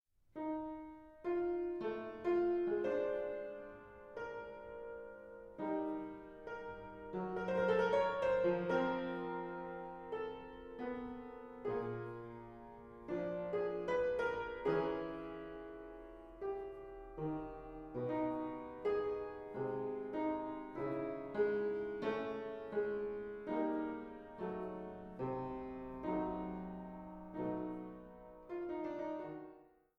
Fortepiano und Clavichord